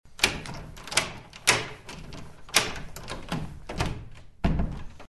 Звуки дверного замка
Звук поворота замка в деревянной двери несколько оборотов